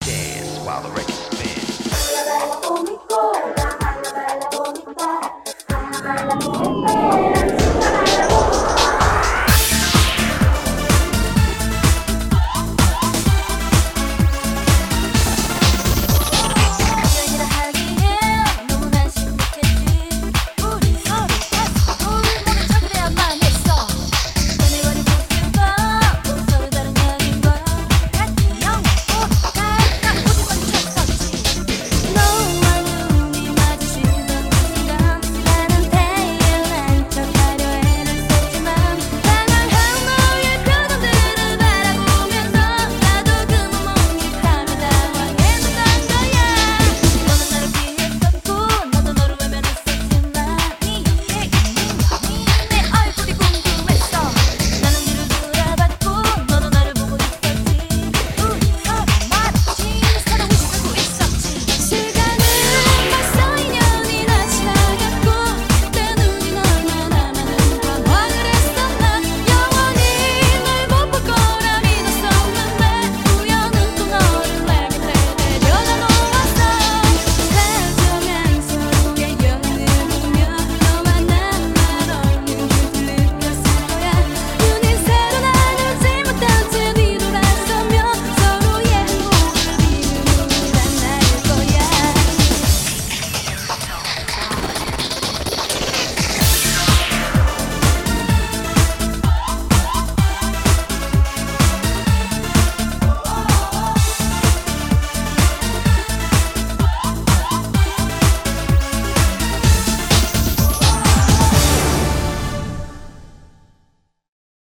BPM127
Audio QualityPerfect (High Quality)
K-POP song